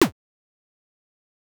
8bit_FX_Shot_01_03.wav